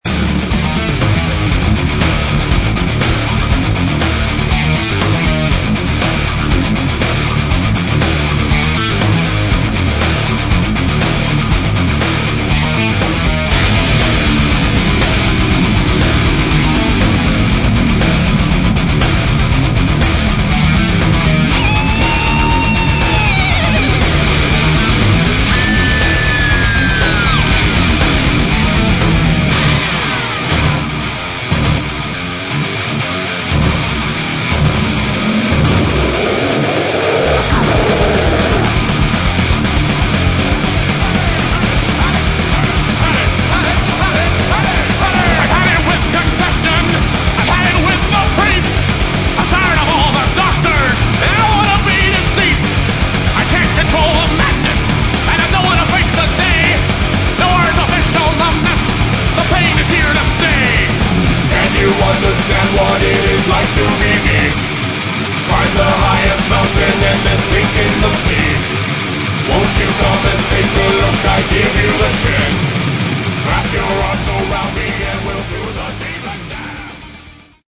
Metal
Эффекты, обработки, качественная в общем запись.